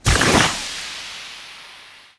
launch_alt_fire.wav